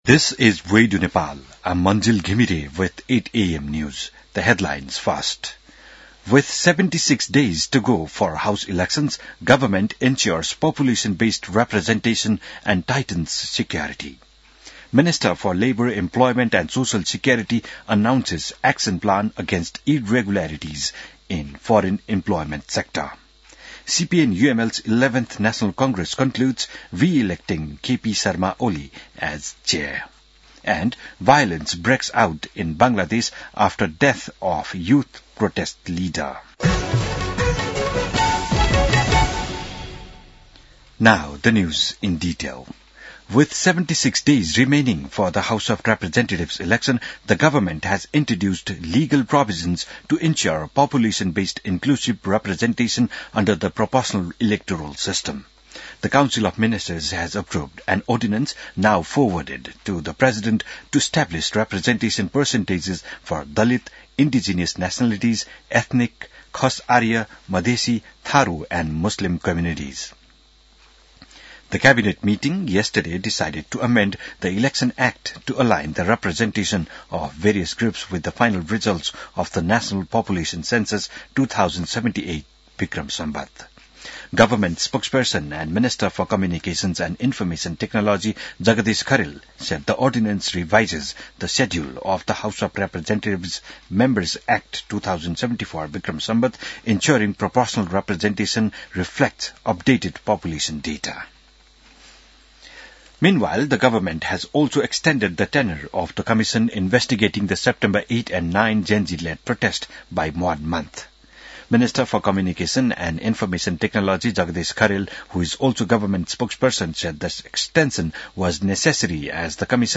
बिहान ८ बजेको अङ्ग्रेजी समाचार : ४ पुष , २०८२